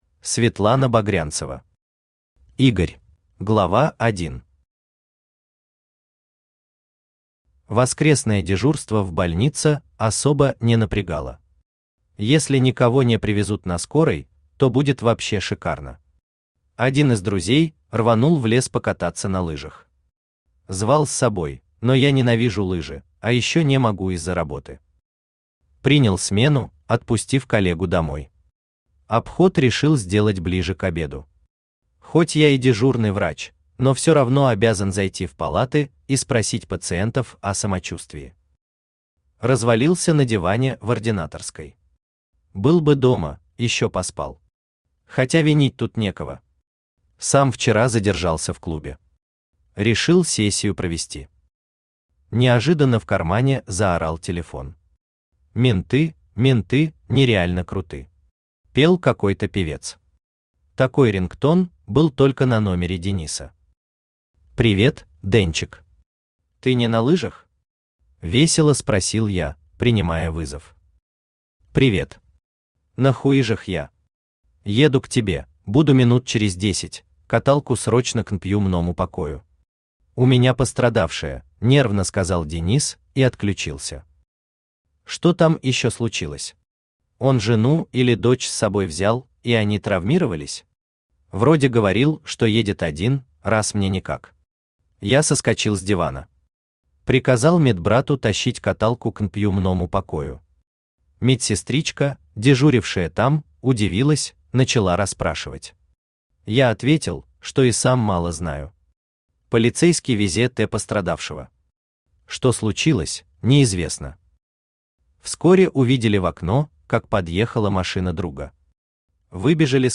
Аудиокнига Игорь | Библиотека аудиокниг
Aудиокнига Игорь Автор Светлана Багрянцева Читает аудиокнигу Авточтец ЛитРес.